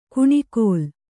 ♪ kuṇikōl